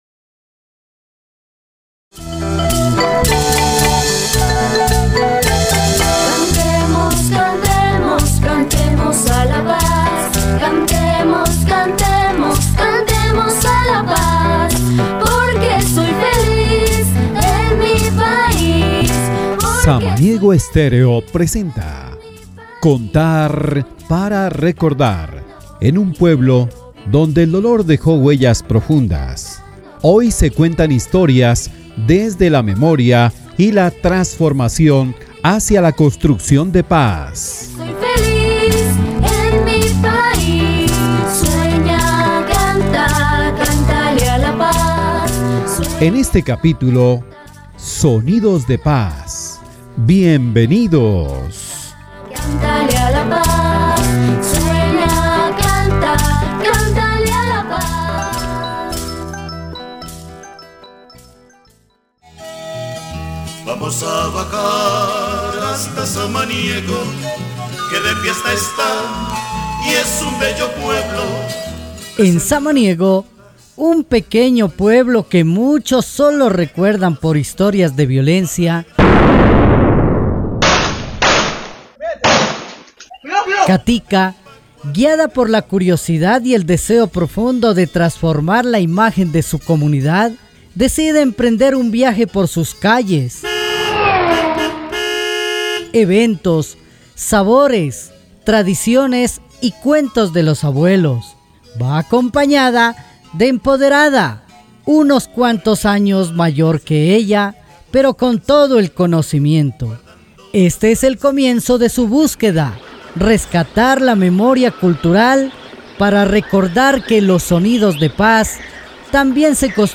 Este podcast explora el canto como forma de recordar y preservar la memoria histórica del territorio. A través de la voz y la música, se transmiten historias, dolores y resistencias que no deben olvidarse.